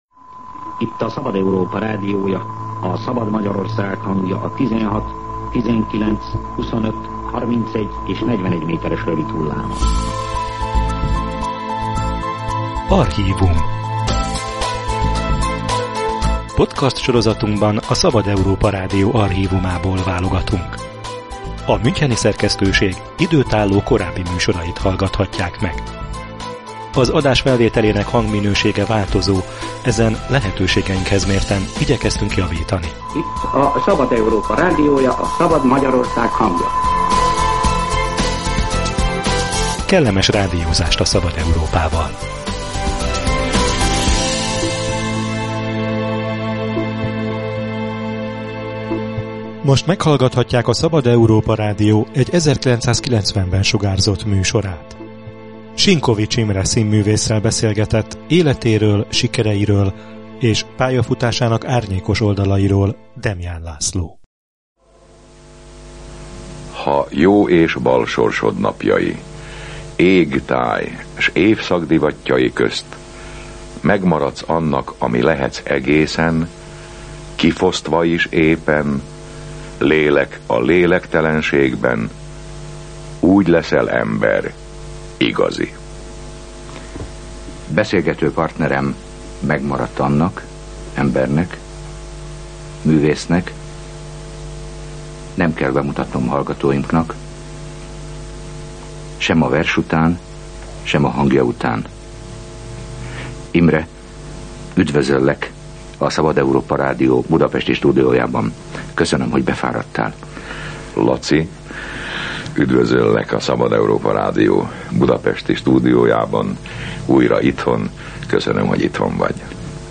A forradalom napjaiban aktívan részt vett a művészvilág szervezésében, később csak a népszerűsége mentette meg a börtöntől. Sinkovits Imrét 1990-ben kérte visszaemlékező beszélgetésre a Szabad Európa Rádió.